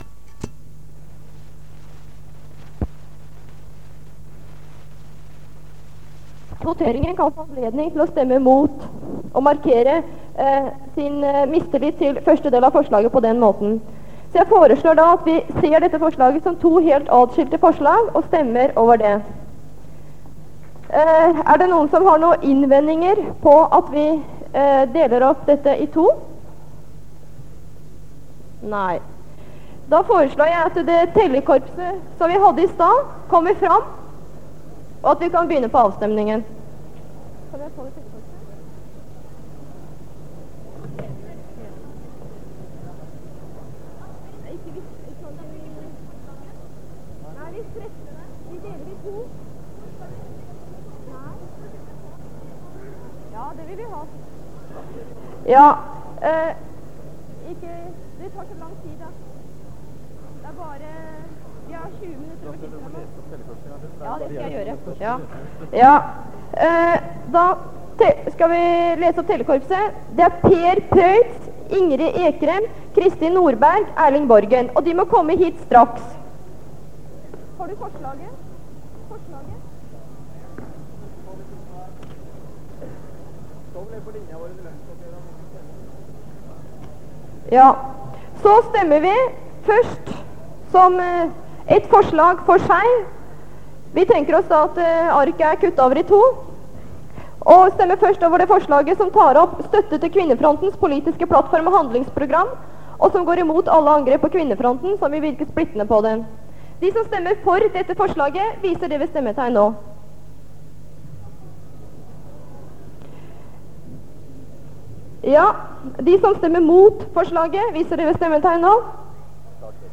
Det Norske Studentersamfund, Generalforsamling, 11.05.1974